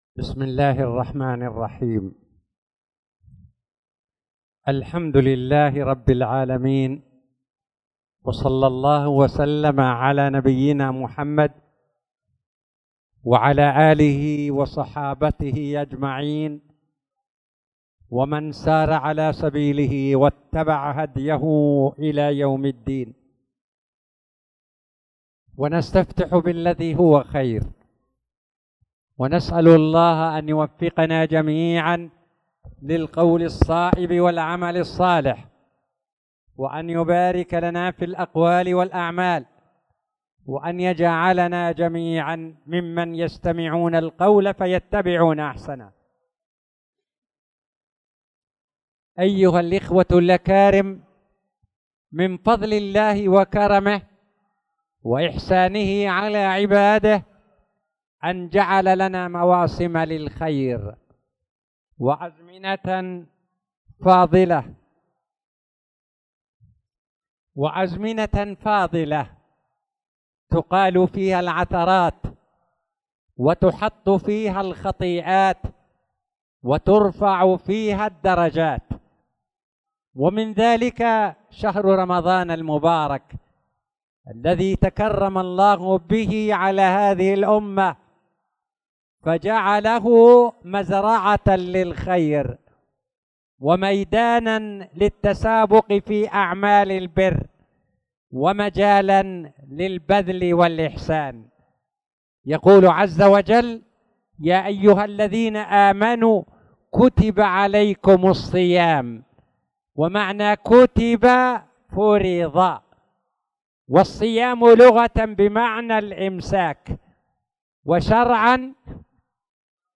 تاريخ النشر ٢٩ شعبان ١٤٣٧ هـ المكان: المسجد الحرام الشيخ